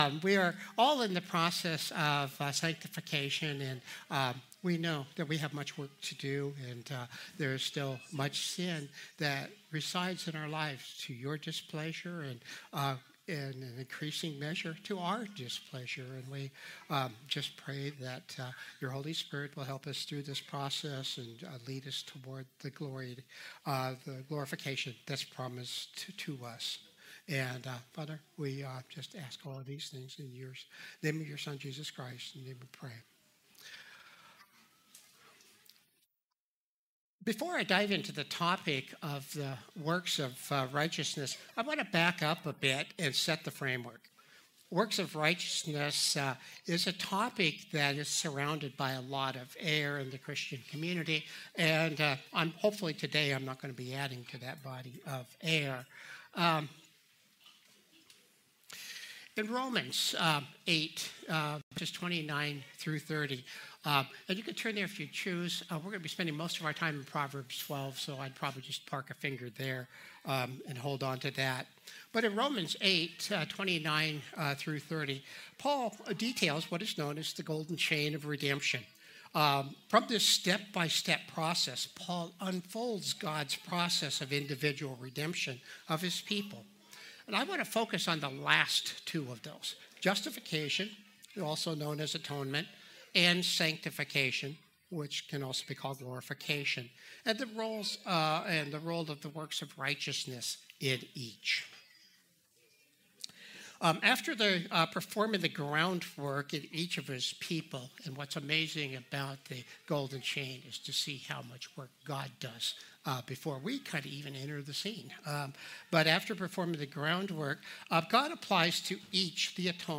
Christ the Redeemer Church | Sermons